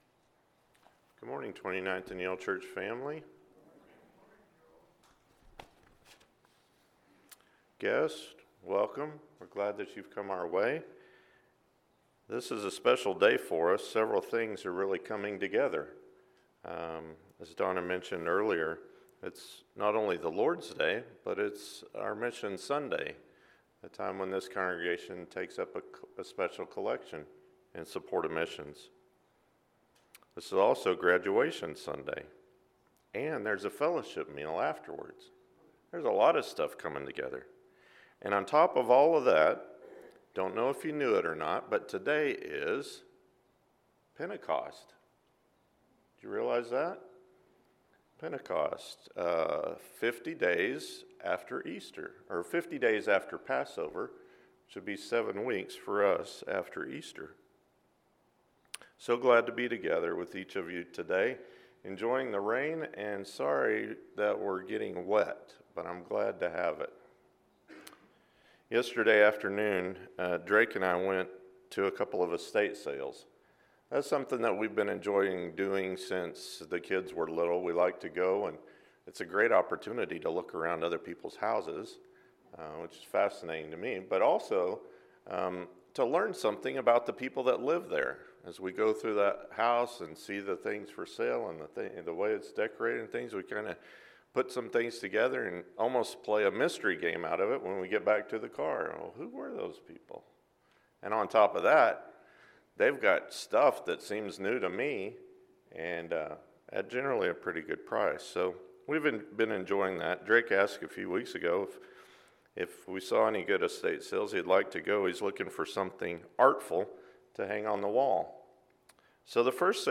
God’s Handiwork – Ephesians 2:1-10 – Sermon